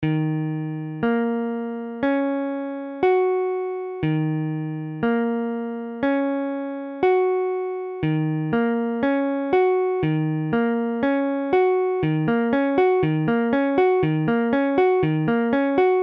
Mesure : 4/4
Tempo : 1/4=60
VI_Ebm7.mp3